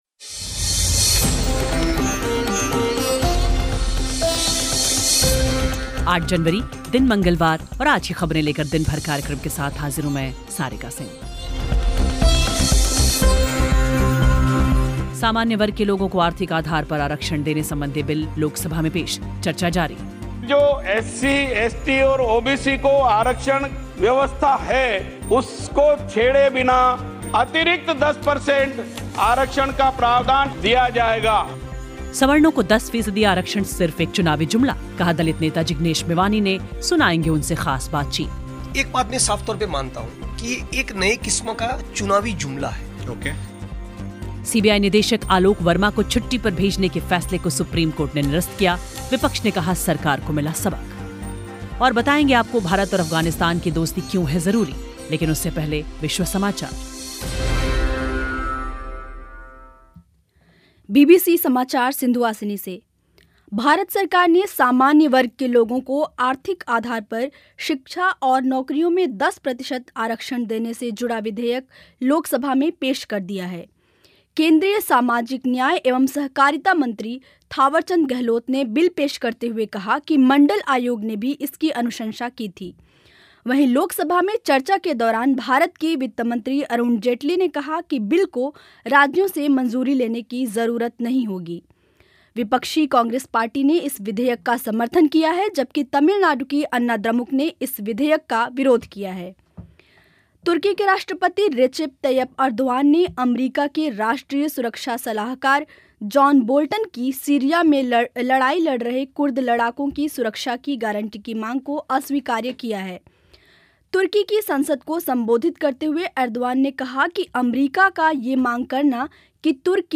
सवर्णों को 10 फीसदी आरक्षण सिर्फ एक चुनावी जुमला ..कहा दलित नेता जिग्नेश मेवानी ने ...सुनाएंगे उनसे ख़ास बातचीत